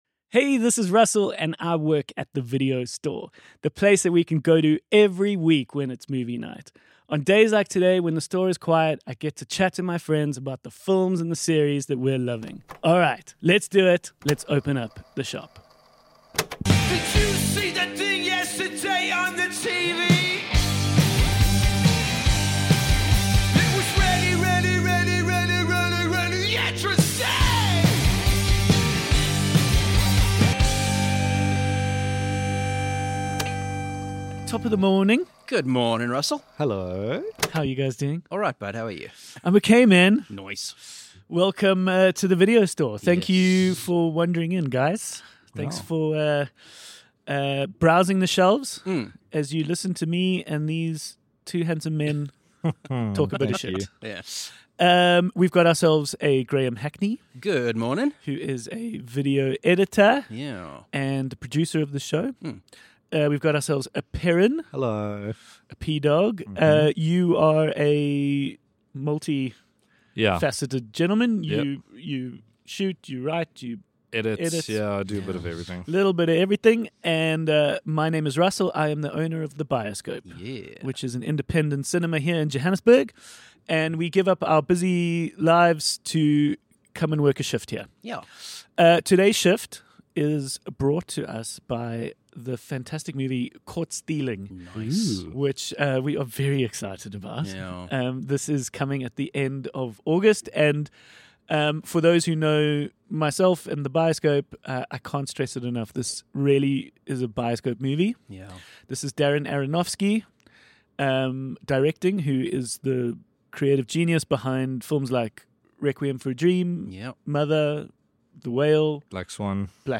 A weekly chat amongst friends working a shift at your local video store.